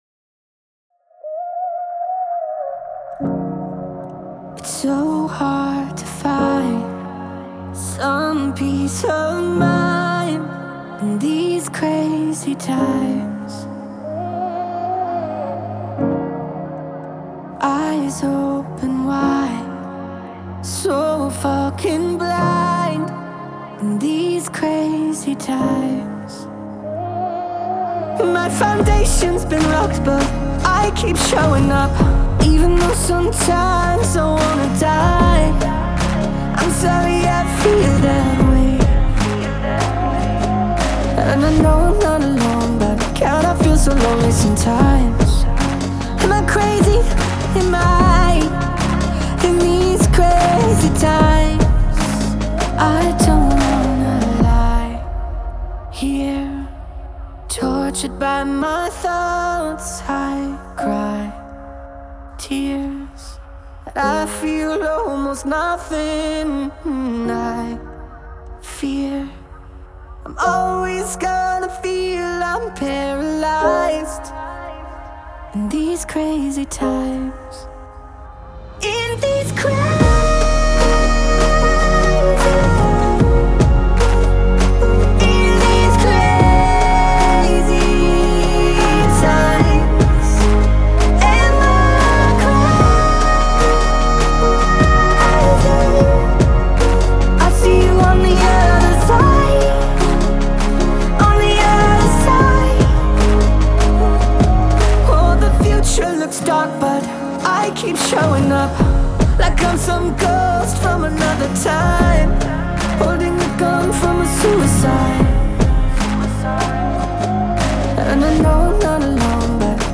это эмоциональный трек в жанре EDM